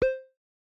chat-notification.mp3